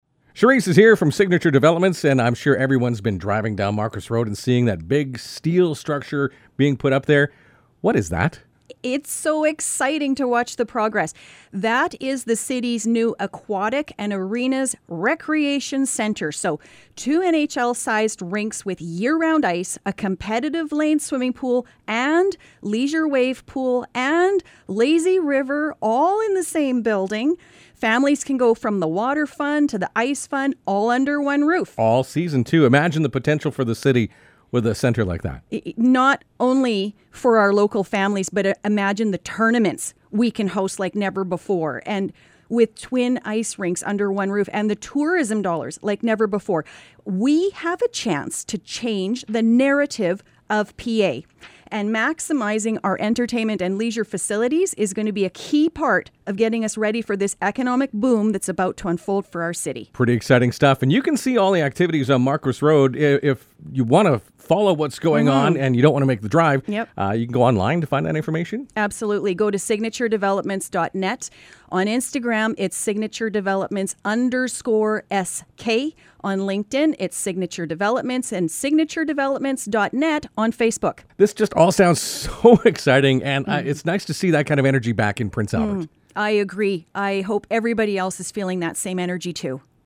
Radio Chat